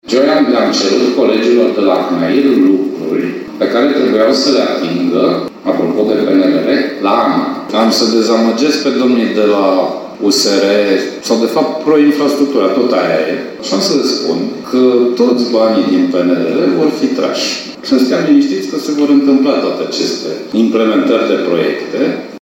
Prezent la Timișoara, ministrul Sorin Grindeanu a contrazis unii contestatari și a declarat că toți banii pe infrastuctură rutieră din PNRR vor fi atrași de Ministerul Transporturilor.
Sorin-Grindeanu-autostrada.mp3